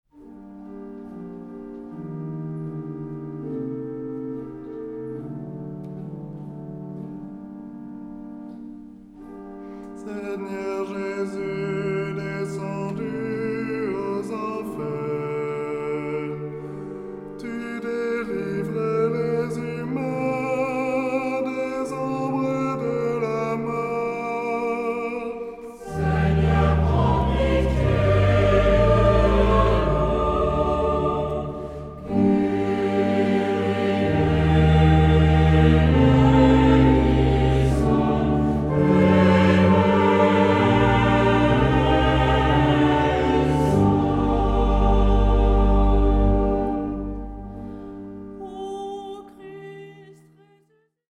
Genre-Style-Form: Litany
Mood of the piece: prayerful
Type of Choir: SATB  (4 mixed voices )
Instruments: Organ (1)
Tonality: G minor